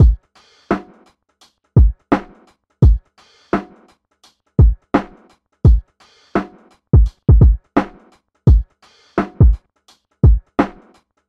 尘埃落定时髦的Lofi 嘻哈 Trip Hop节拍 85 BPM
Tag: 85 bpm Hip Hop Loops Drum Loops 1.90 MB wav Key : Unknown